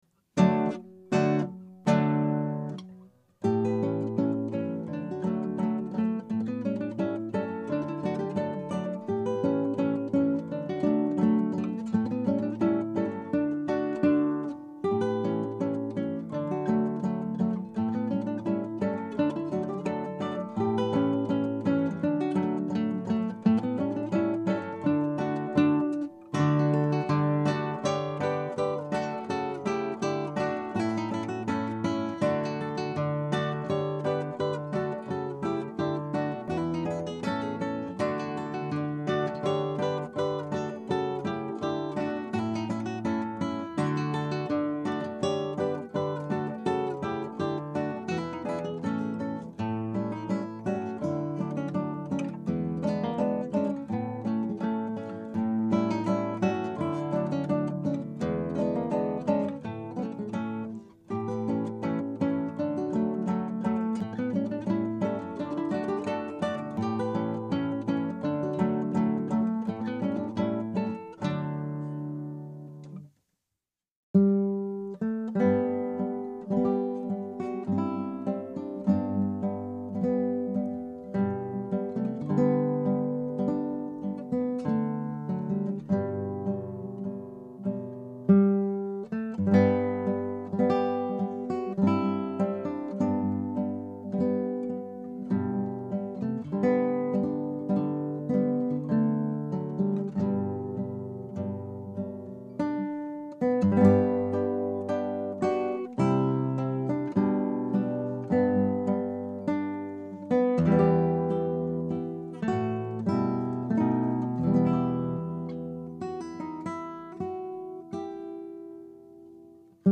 Scraps from the Operas arranged for Two Guitars
Scrap 1: Scherzando.
Scrap 2 (1:14): Adagio con molto espress.
Scrap 3 (2:51): Tempo primo.
Reprise of Scrap 1.